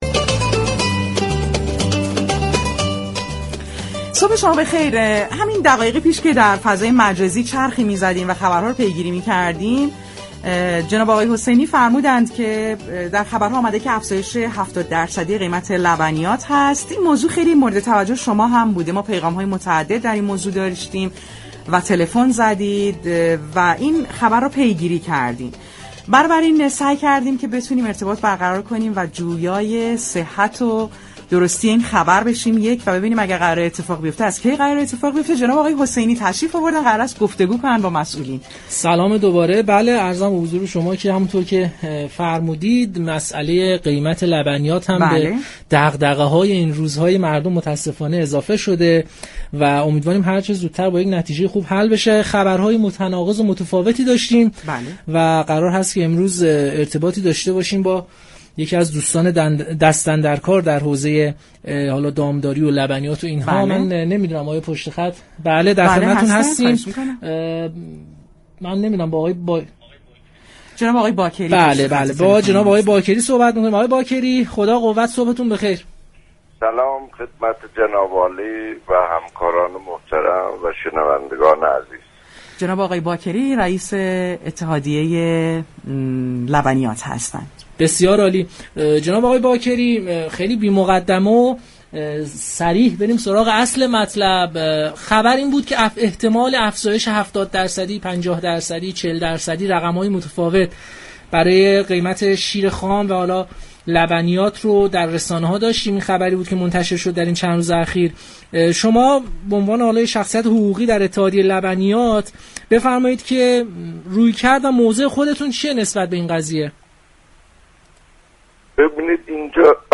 در گفتگو با برنامه پارك شهر